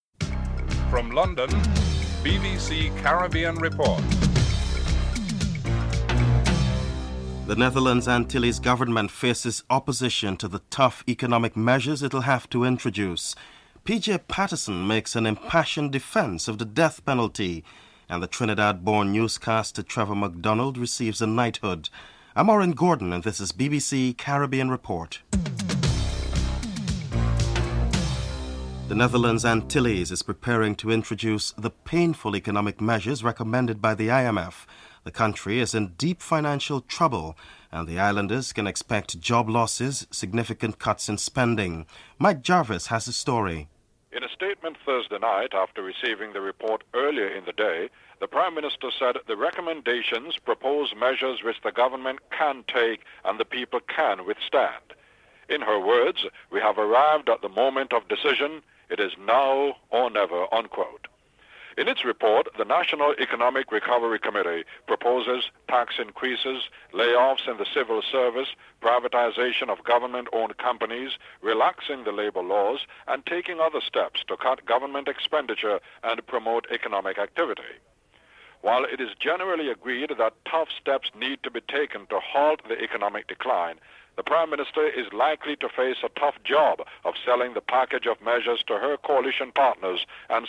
Colin Croft reviews the game and previews upcoming matches (08: 19 - 11: 18)